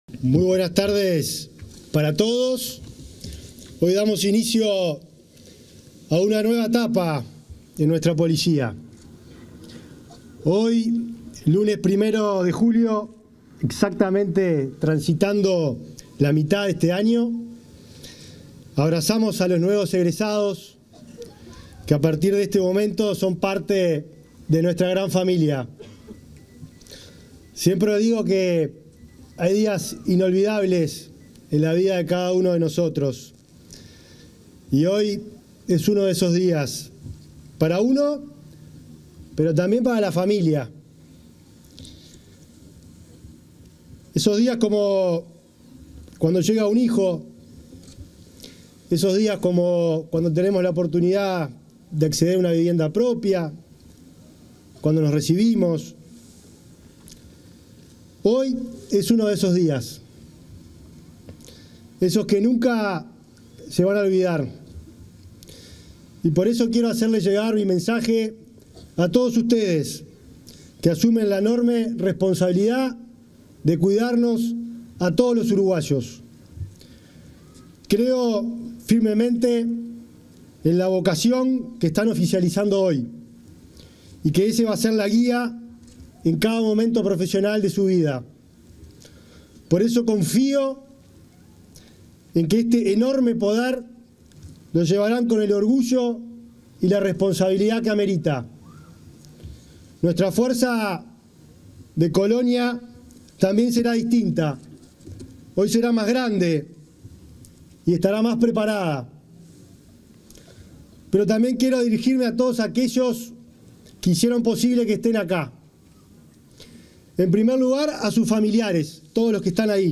Palabras del ministro del Interior, Nicolás Martinelli
Palabras del ministro del Interior, Nicolás Martinelli 01/07/2024 Compartir Facebook X Copiar enlace WhatsApp LinkedIn En el marco de la ceremonia de egreso de la XCVII Promoción de Agentes de la Escuela Policial de Escala Básica, este 1 de julio, se expresó el ministro del Interior, Nicolás Martinelli.